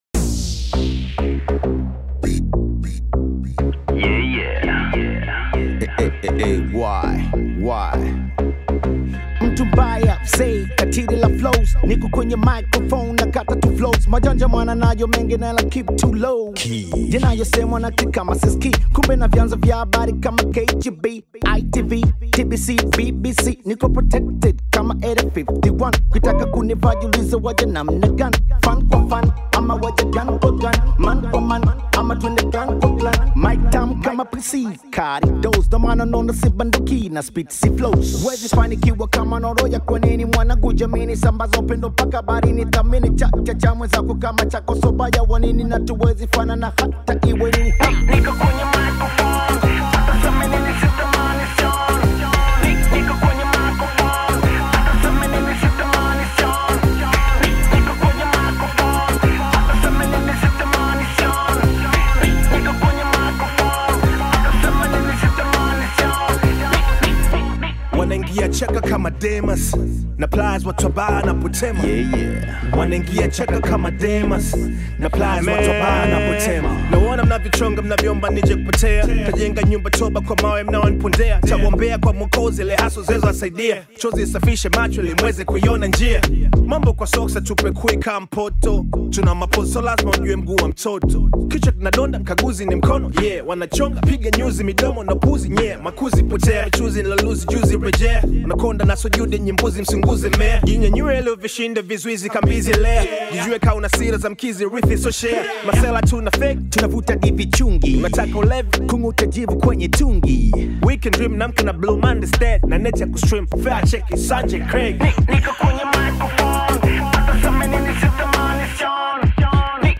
Hip Hop
Rap